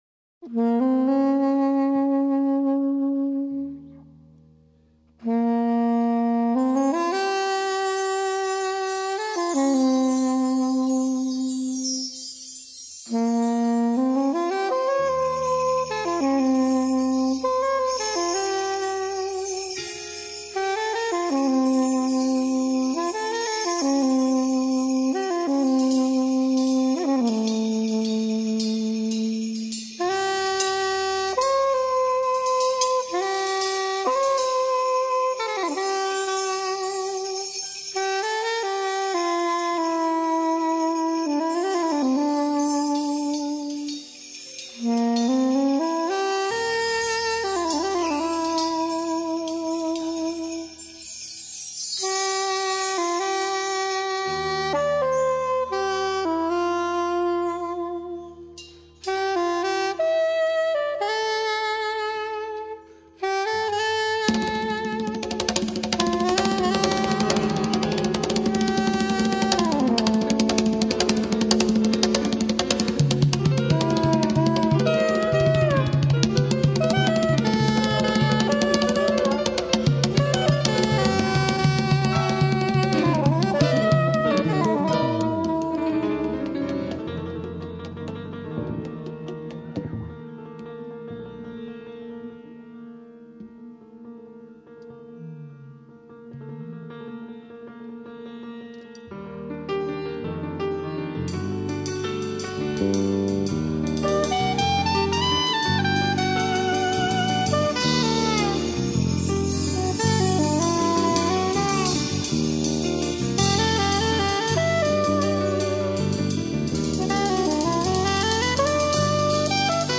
1322   06:34:00   Faixa:     Jazz